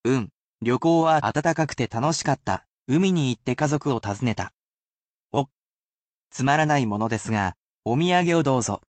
[casual speech]